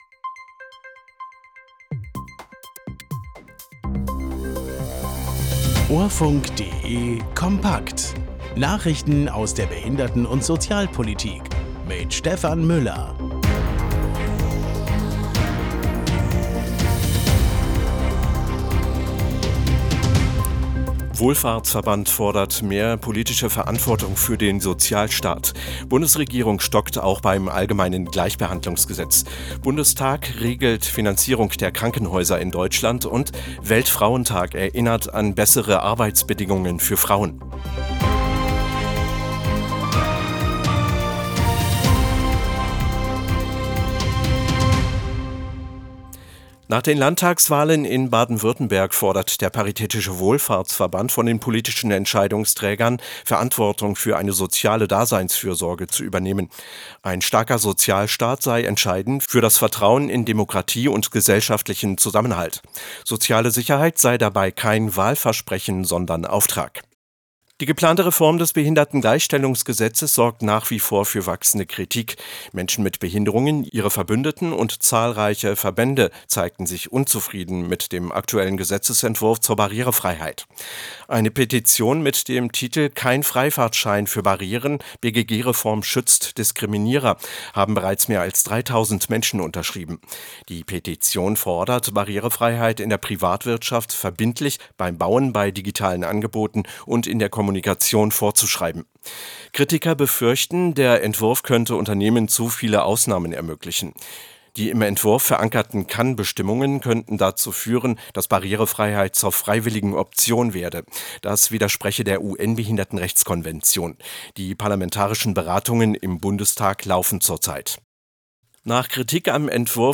Nachrichten aus der Behinderten- und Sozialpolitik vom 11.03.2026